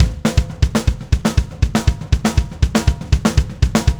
Power Pop Punk Drums 03a.wav